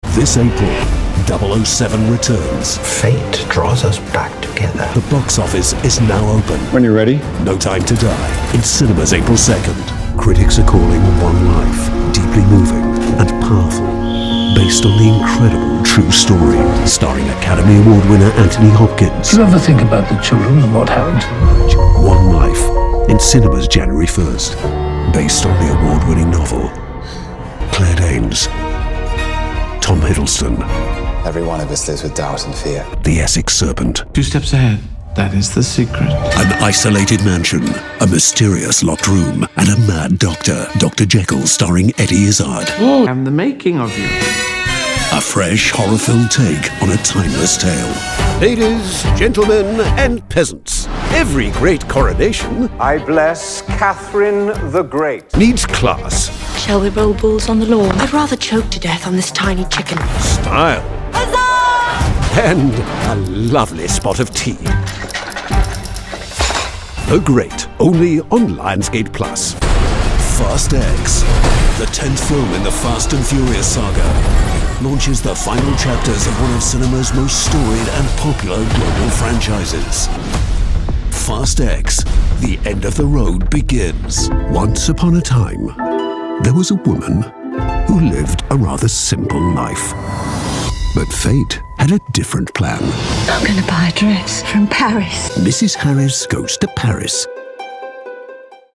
Trailer demo
Contemporary British English; English RP; London; Yorkshire; Liverpool; Scottish; West Country; Various European; Standard American
Middle Aged